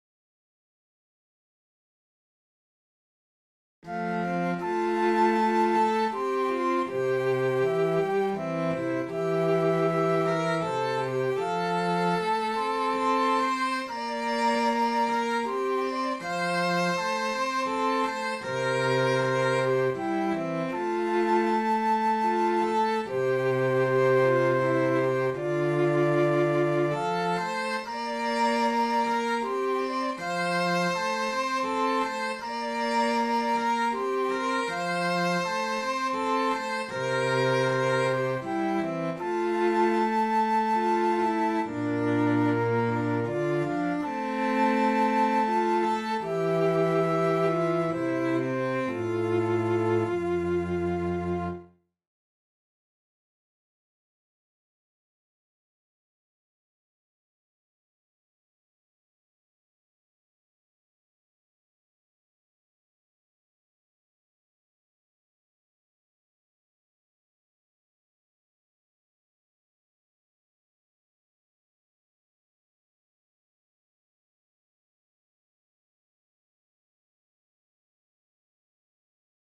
Alhaalla-sellot-ja-huilu.mp3